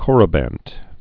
(kôrə-bănt, kŏr-)